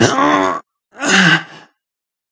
Cry2.ogg